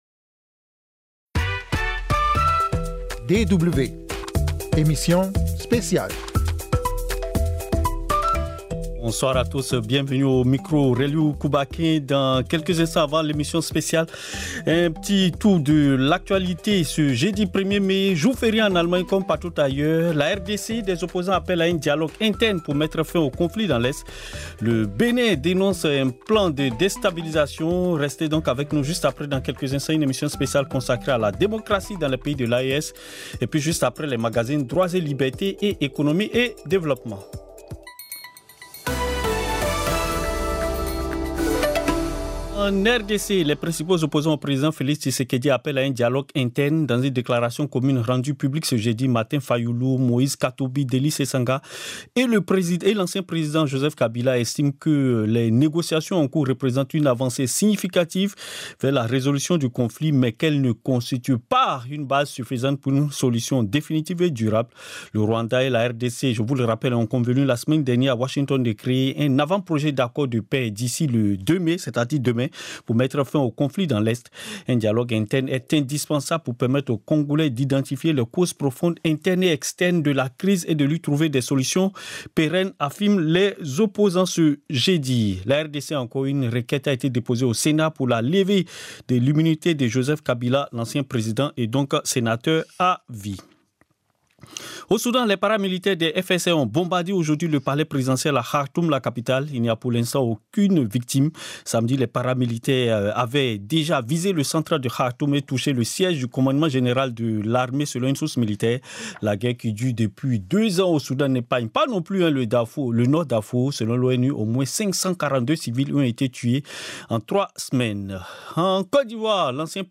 Journal d'informations internationales et africaines.